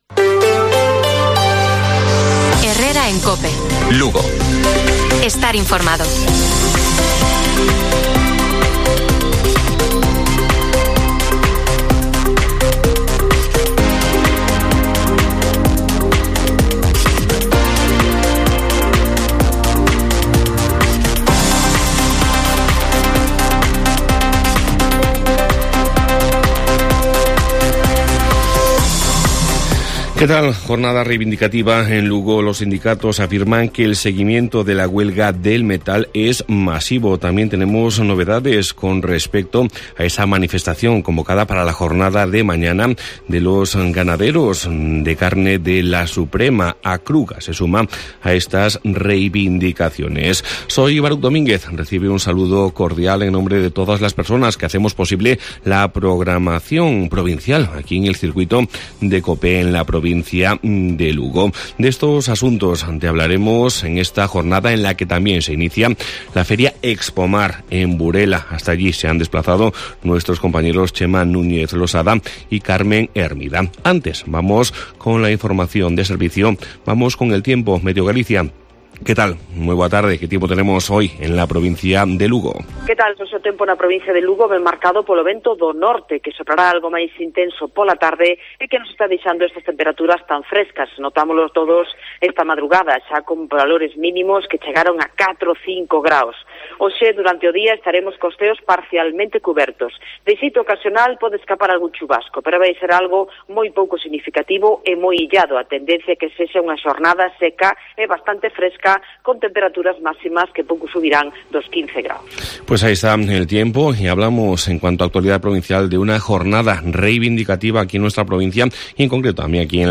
Informativo Provincial de Cope Lugo. 10 de mayo. 12:50 horas